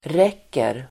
Uttal: [r'ek:er]